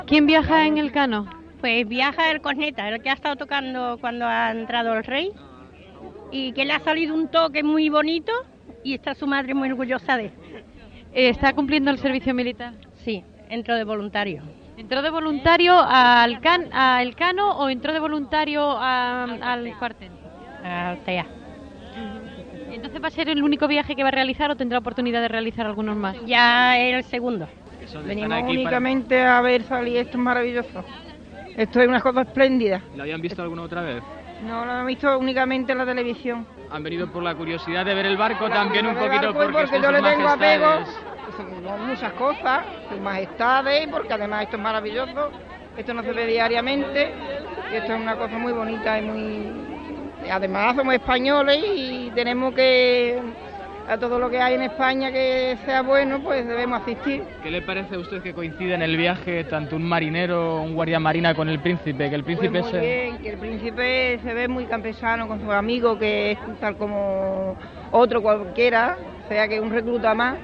Testimoni de la mare d'un dels mariners.
Informatiu